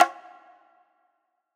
Perc (14).wav